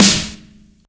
• Clicky Steel Snare Drum Sample G# Key 42.wav
Royality free snare drum tuned to the G# note. Loudest frequency: 4033Hz
clicky-steel-snare-drum-sample-g-sharp-key-42-AdD.wav